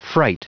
Prononciation du mot fright en anglais (fichier audio)
fright.wav